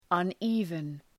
Προφορά
{ʌn’i:vən}